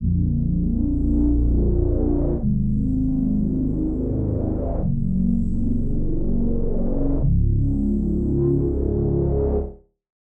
AV_Scream_Bass_100bpm_Bbmin
AV_Scream_Bass_100bpm_Bbmin.wav